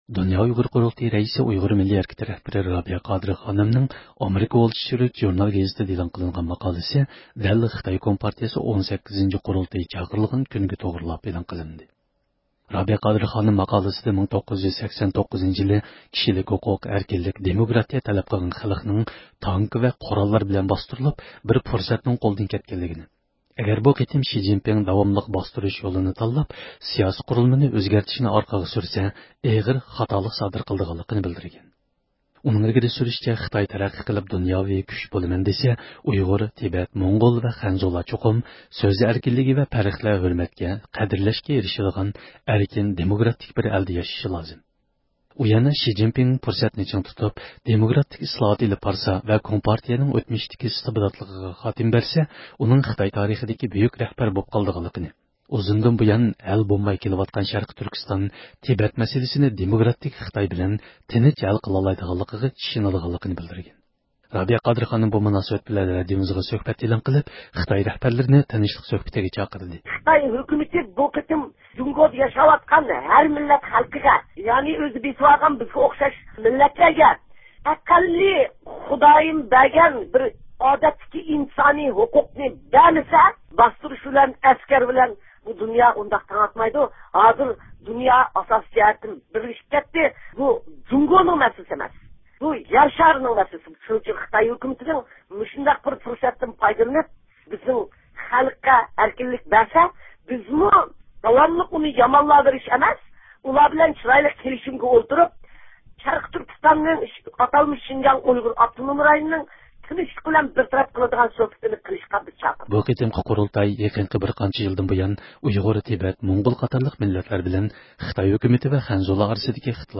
رابىيە قادىر خانىم بۇ مۇناسىۋەت بىلەن رادىئومىزغا سۆھبەت ئېلان قىلىپ، خىتاي رەھبەرلىرىنى تىنچلىق سۆھبىتىگە چاقىردى.